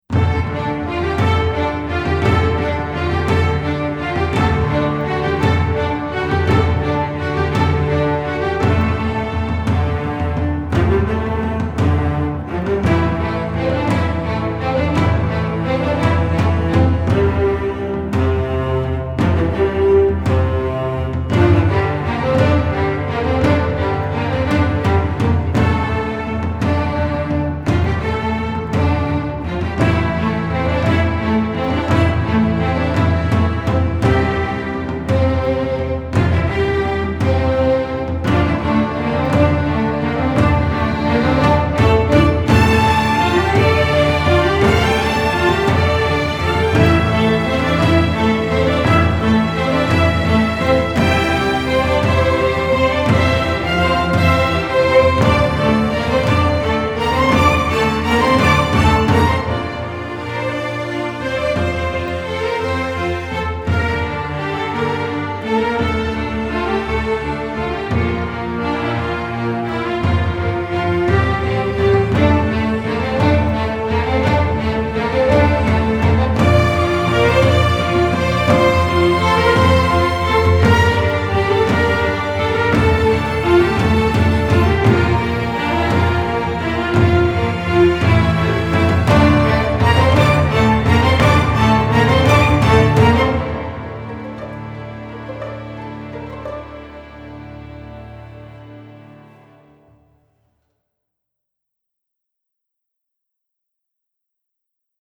현악 편곡은